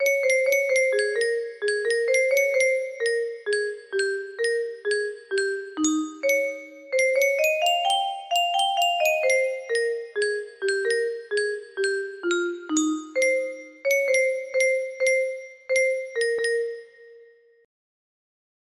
remaster suffering music box melody
Full range 60